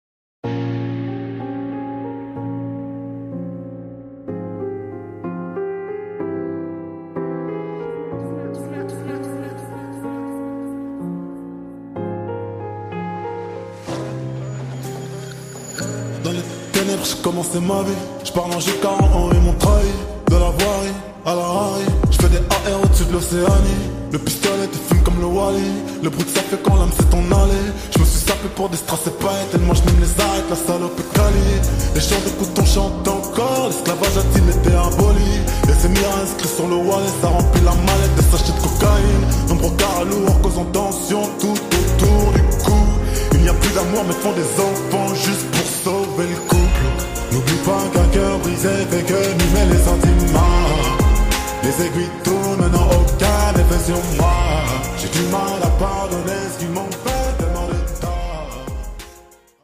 8D EXPERIENCE 🎧